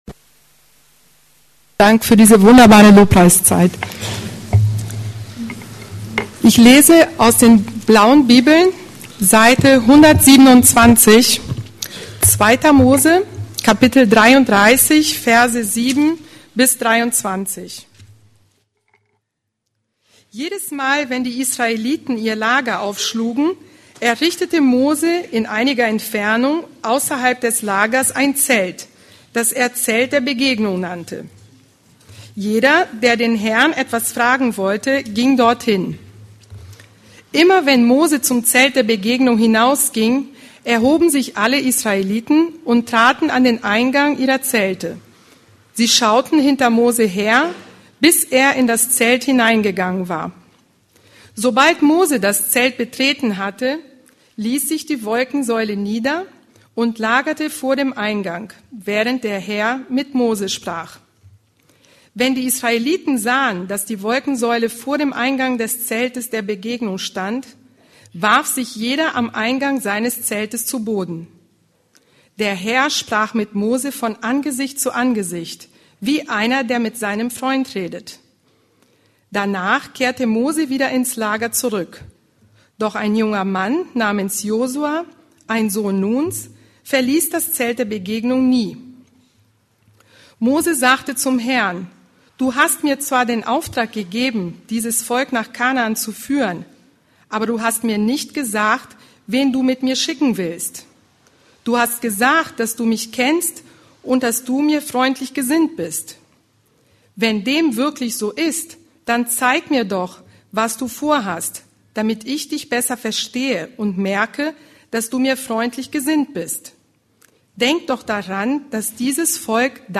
Heilige Sehnsüchte und Leidenschaften: Moses ~ Predigten der LUKAS GEMEINDE Podcast